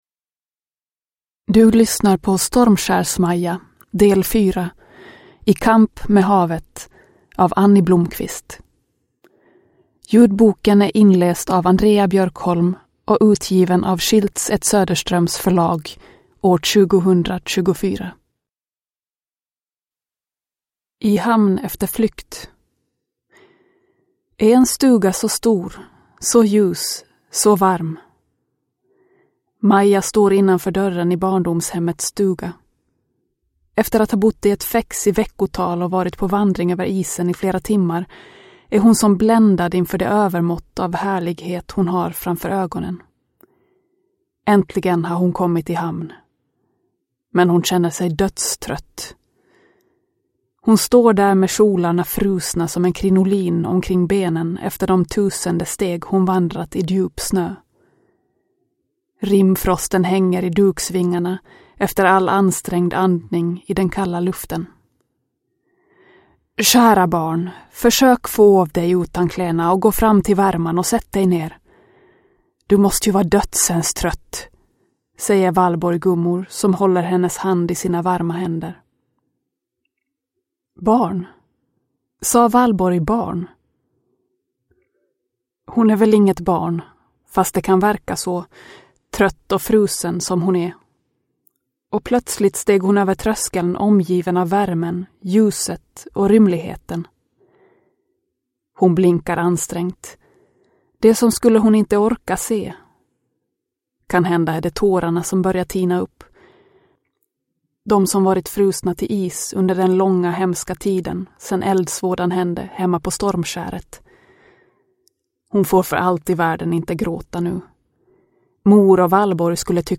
Stormskärs Maja del 4. I kamp med havet (ljudbok) av Anni Blomqvist